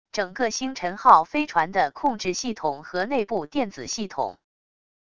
整个星辰号飞船的控制系统和内部电子系统wav音频